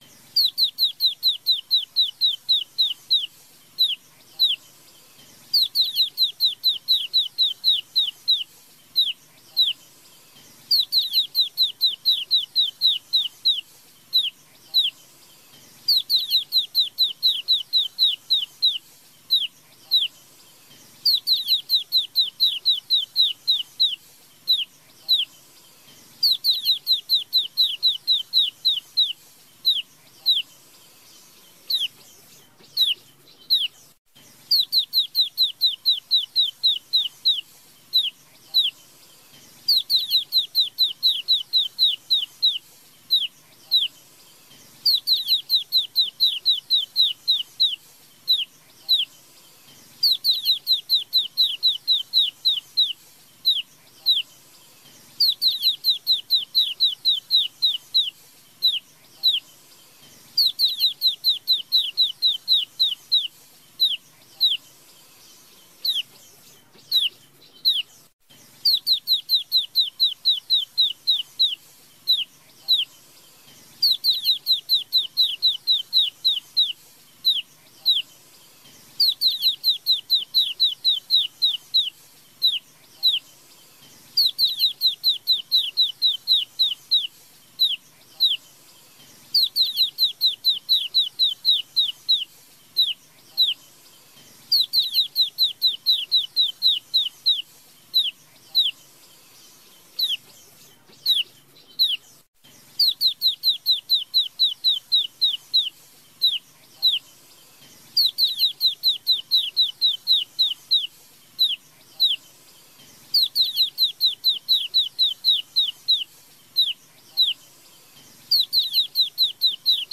Tiếng chim Sâu Xanh mái
Tải tiếng chim Sâu Xanh mp3 chất lượng cao, không có tạp âm, là lựa chọn tuyệt vời cho các dự án video cảnh thiên nhiên, núi rừng, hoang dã, giúp tăng tính chân thực và hấp dẫn cho nội dung.
Tiếng của chim mái thường là những tiếng "chích... chích..." đơn lẻ, thanh mảnh nhưng có tần số lặp lại nhanh và đều đặn. Đây là tín hiệu mời gọi bạn tình hoặc thông báo vị trí, có tác dụng làm cho chim trống trở nên hăng hái, siêng hót và dạn dĩ hơn rất nhiều.